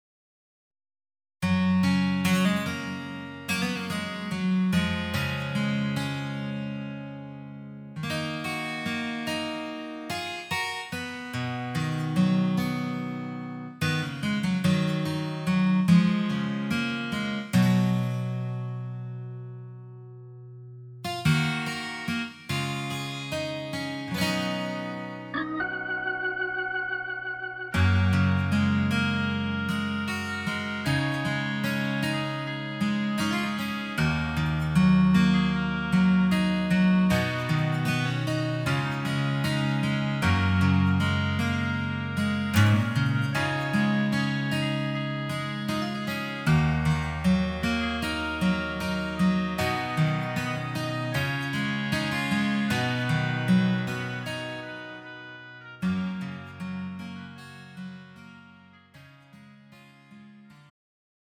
음정 -1키 4:35
장르 가요 구분 Pro MR